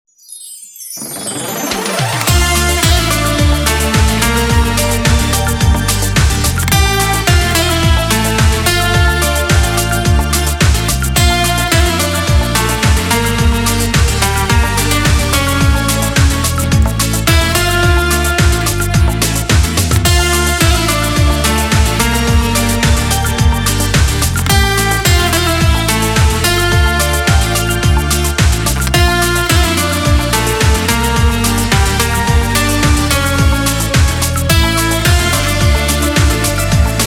• Качество: 320, Stereo
красивые
без слов
инструментальные
шансон